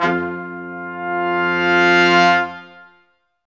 Index of /90_sSampleCDs/Roland LCDP06 Brass Sections/BRS_Quintet sfz/BRS_Quintet sfz